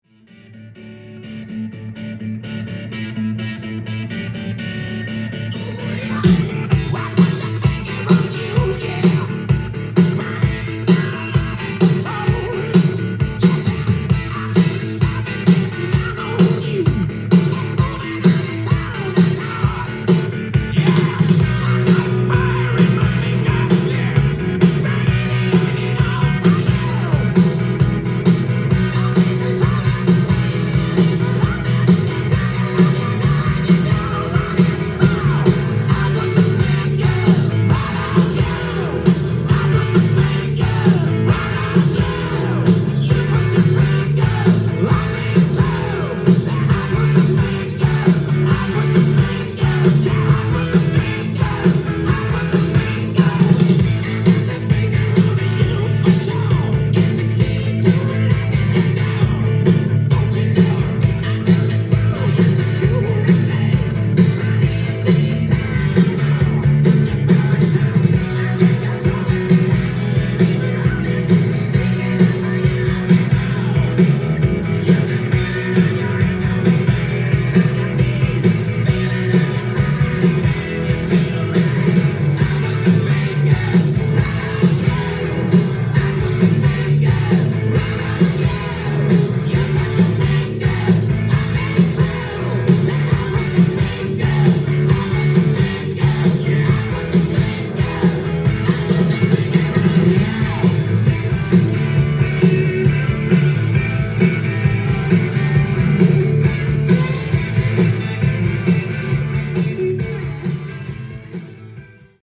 ジャンル：HARD-ROCK
店頭で録音した音源の為、多少の外部音や音質の悪さはございますが、サンプルとしてご視聴ください。